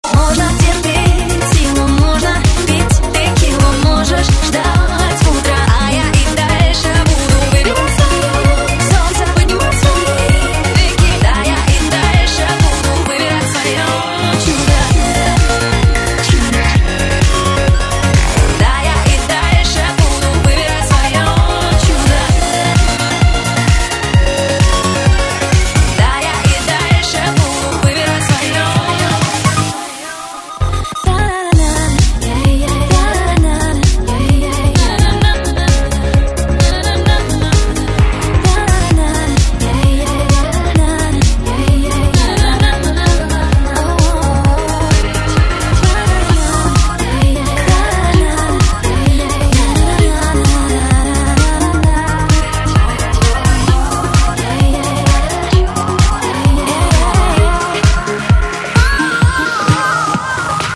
• Качество: 128, Stereo
женский вокал
танцевальная музыка
зажигательная песня